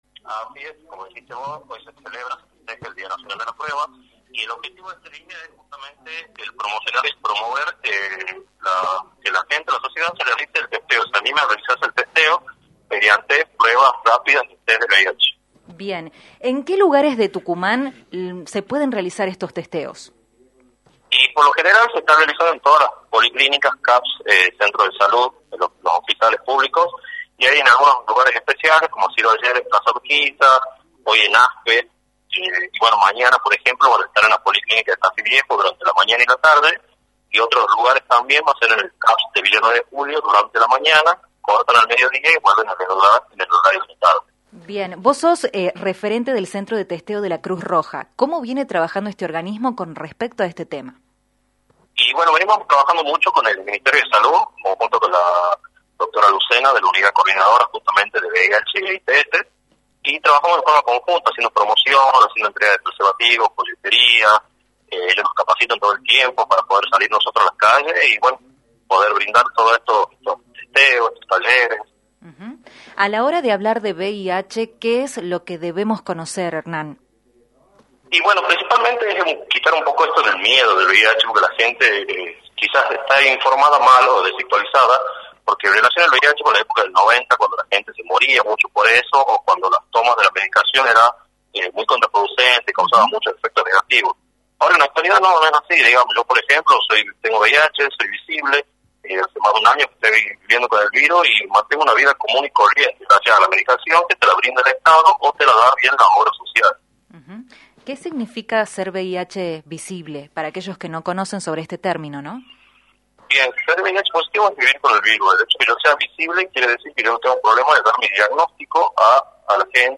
conversó con el equipo de  «Dos a la Tarde»  por la  Rock&Pop FM 106.9, sobre la importancia de informar y prevenir sobre el VIH y SIDA.